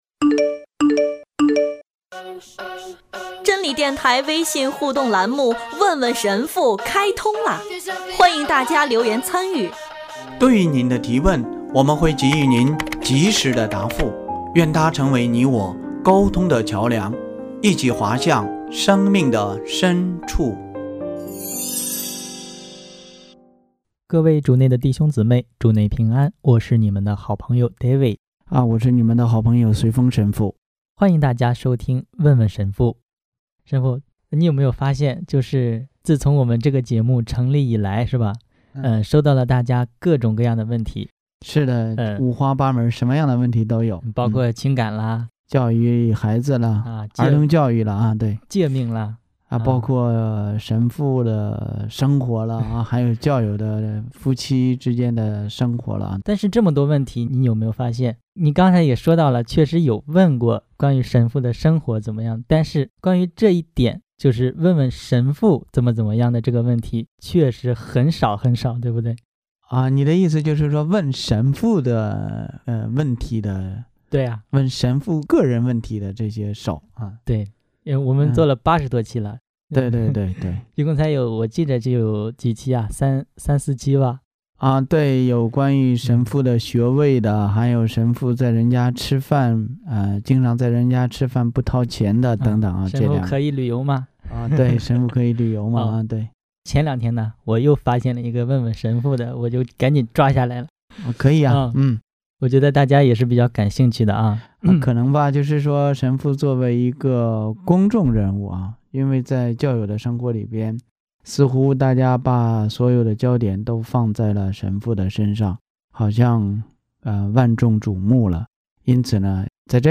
听众留言